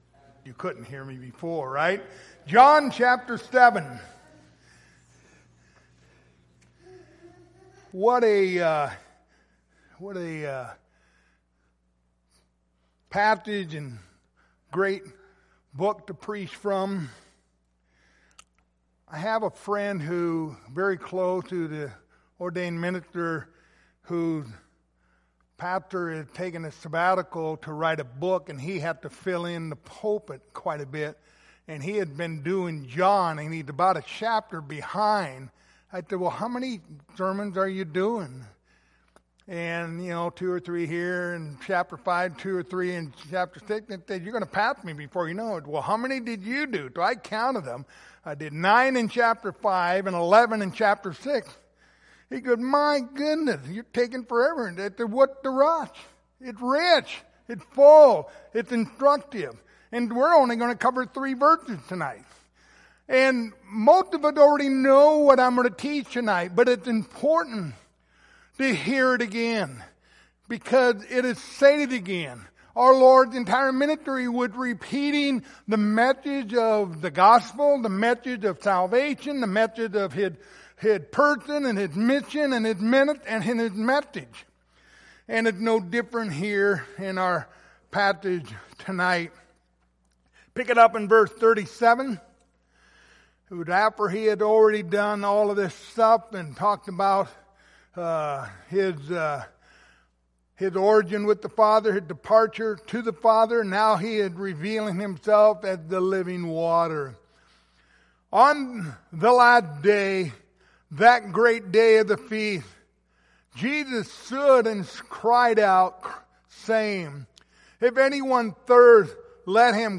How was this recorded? Passage: John 7:37-39 Service Type: Wednesday Evening